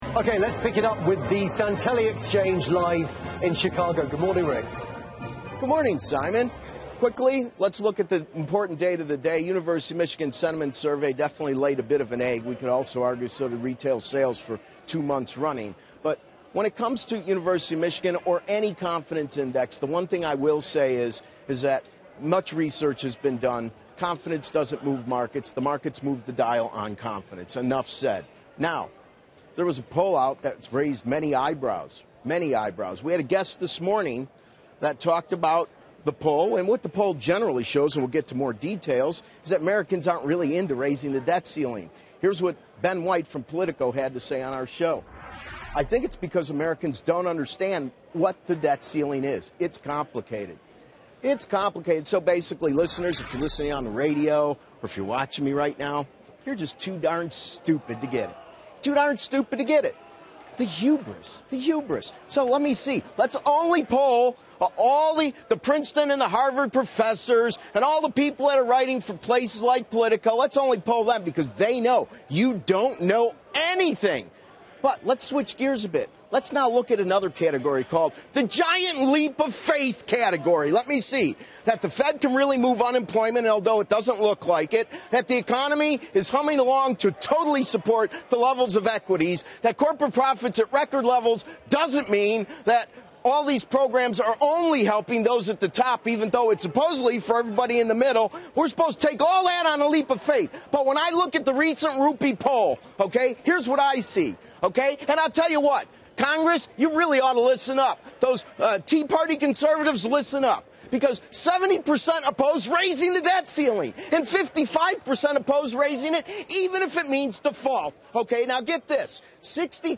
Rick Santelli Screams Reason-Rupe Poll Results on CNBC Business News
"Congress, you really oughta listen up… tea party conservatives, listen up!" yelled Rick Santelli live on CNBC Business News, "70% oppose raising the debt ceiling."